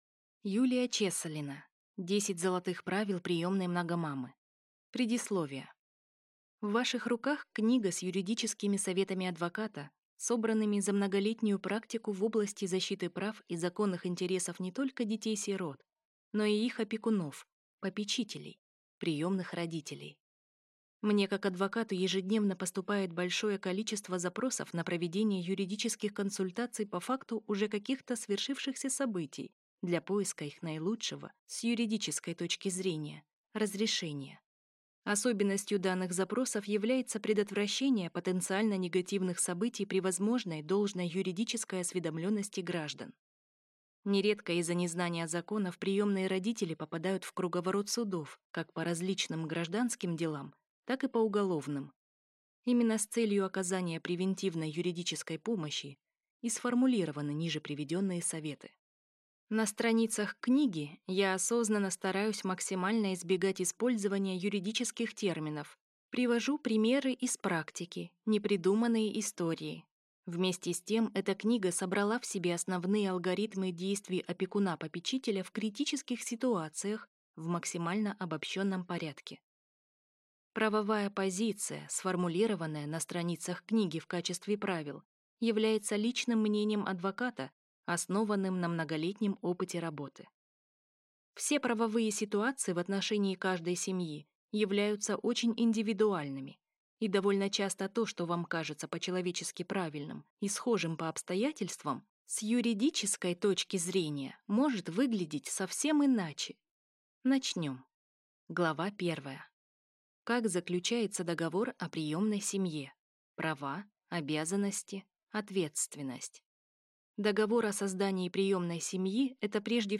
Аудиокнига 10 Золотых правил приемной много-мамы | Библиотека аудиокниг